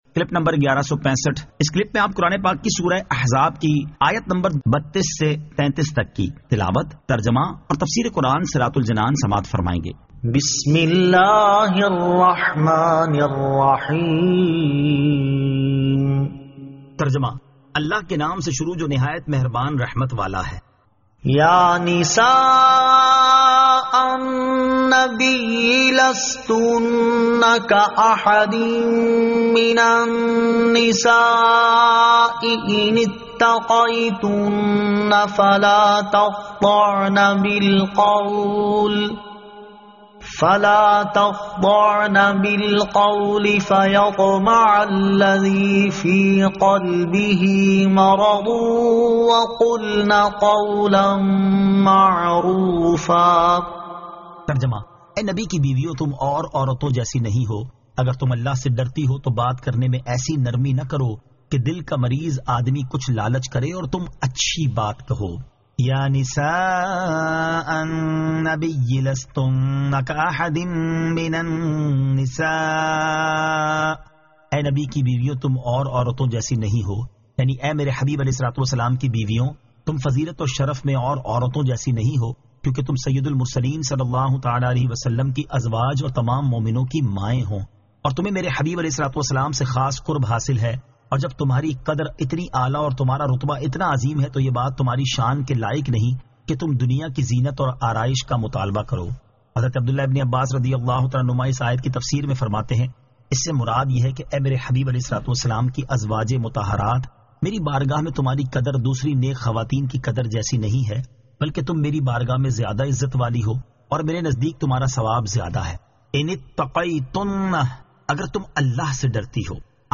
Surah Al-Ahzab 32 To 33 Tilawat , Tarjama , Tafseer